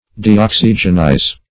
Deoxygenize \De*ox"y*gen*ize\, v. t.